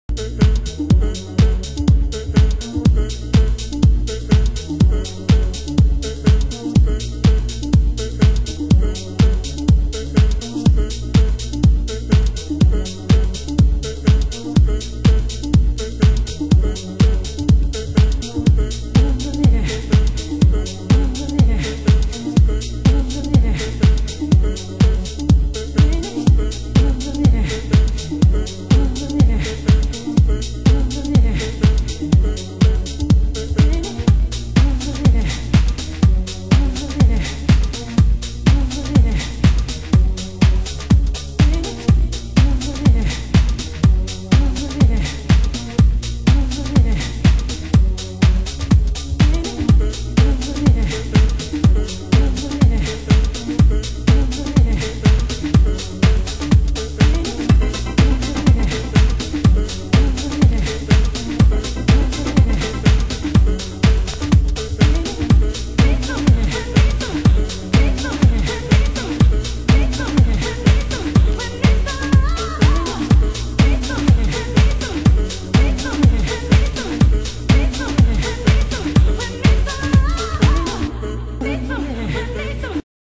HOUSE MUSIC USED ANALOG ONLINE SHOP